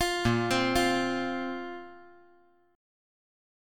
Bbsus2 Chord
Listen to Bbsus2 strummed